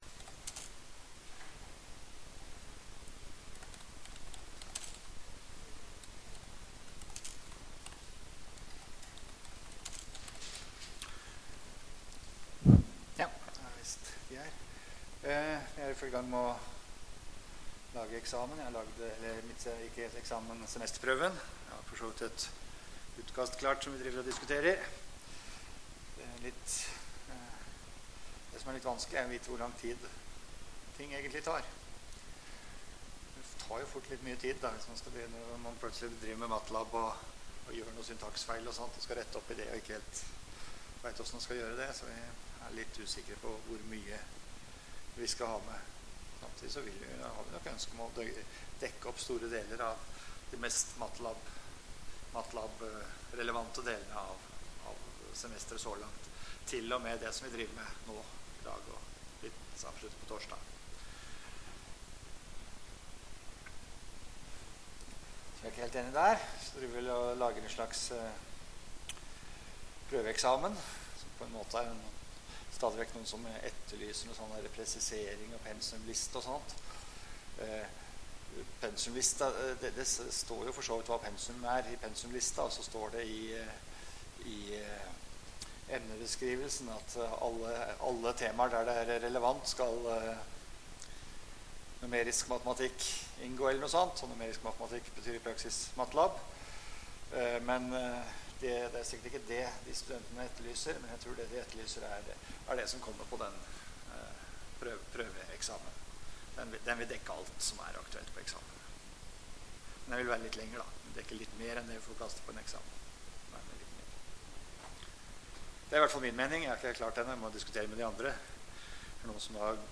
Rom: Lille Eureka, 1/3 Eureka